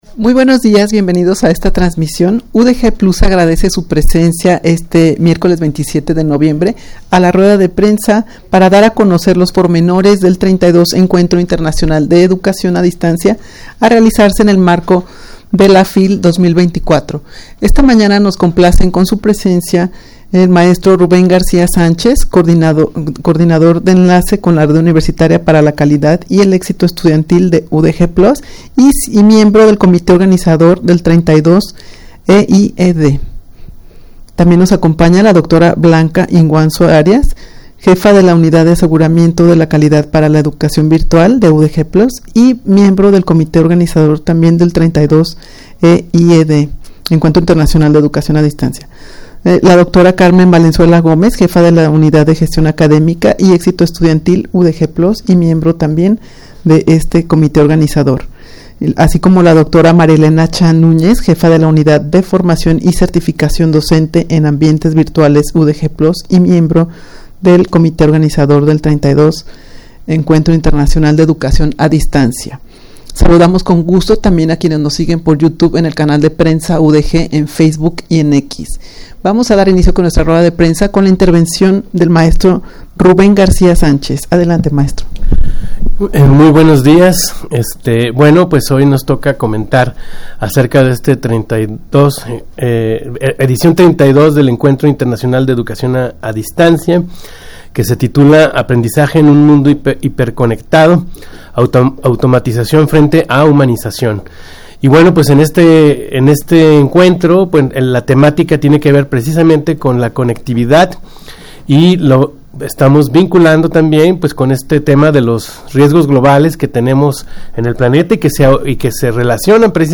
Audio de la Rueda de Prensa
rueda-de-prensa-para-dar-a-conocer-los-pormenores-del-32-encuentro-internacional-de-educacion-a-distancia.mp3